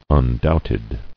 [un·doubt·ed]